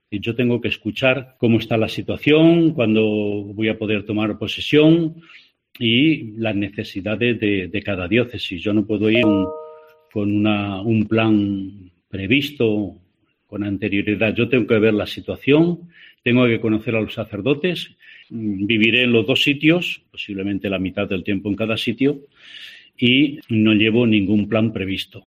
José Luis Retana, Obispo de Salamanca y Ciudad Rodrigo